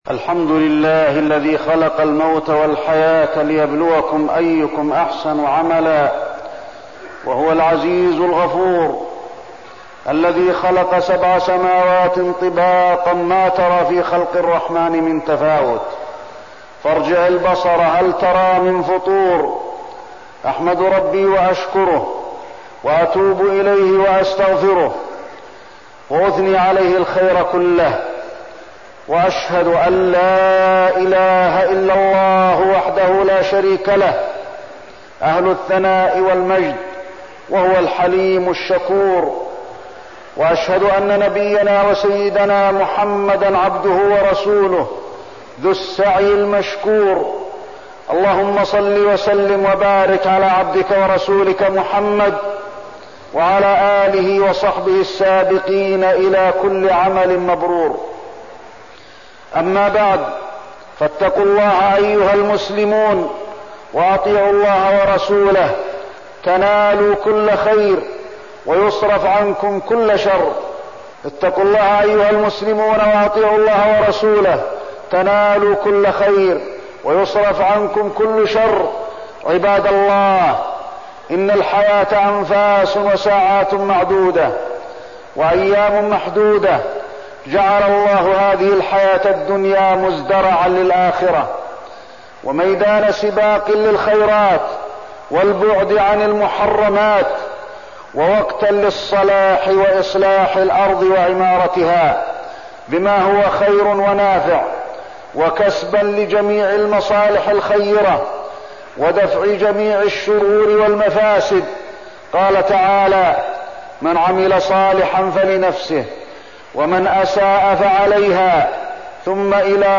تاريخ النشر ٢ جمادى الأولى ١٤١٥ هـ المكان: المسجد النبوي الشيخ: فضيلة الشيخ د. علي بن عبدالرحمن الحذيفي فضيلة الشيخ د. علي بن عبدالرحمن الحذيفي الوقت والفراغ